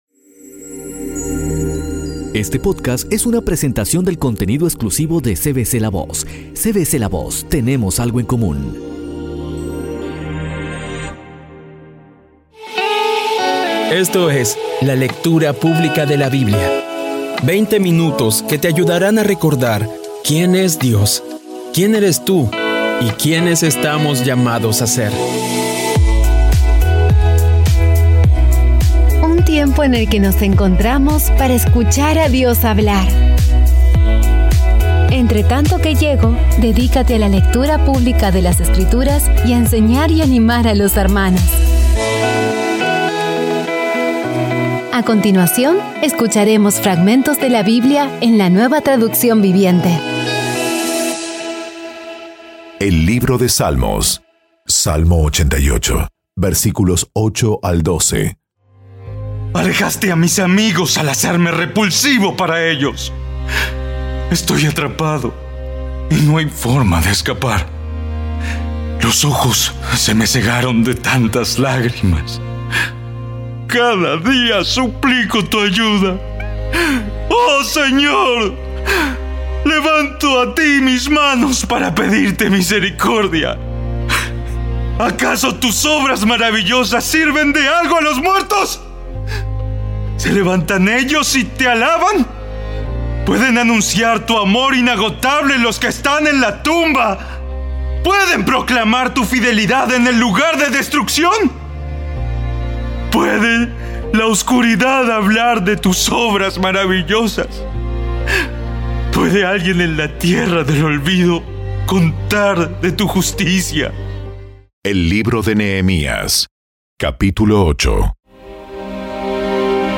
Audio Biblia Dramatizada Episodio 212
Poco a poco y con las maravillosas voces actuadas de los protagonistas vas degustando las palabras de esa guía que Dios nos dio.